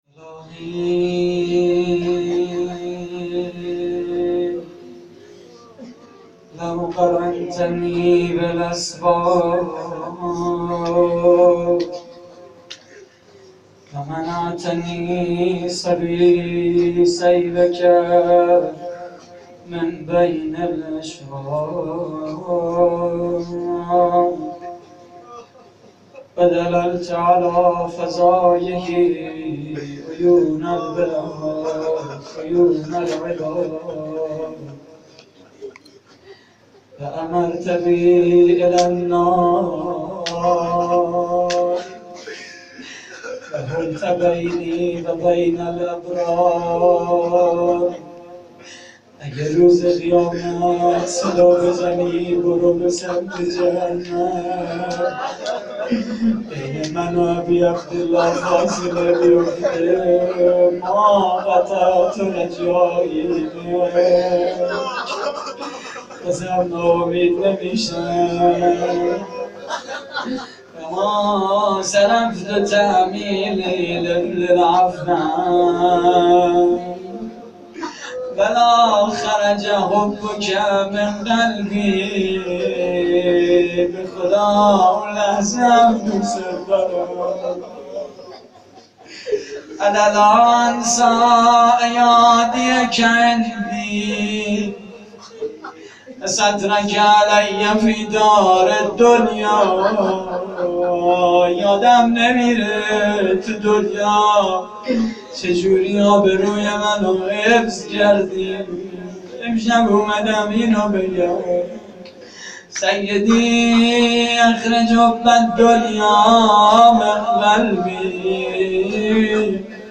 مداحی شب 23 رمضان (مناجات و احیا) / هیئت کریم آل طاها (ع) - 18 تیر 94
صوت مراسم:
مناجات: فرازی از دعای ابوحمزه ثمالی؛ پخش آنلاین |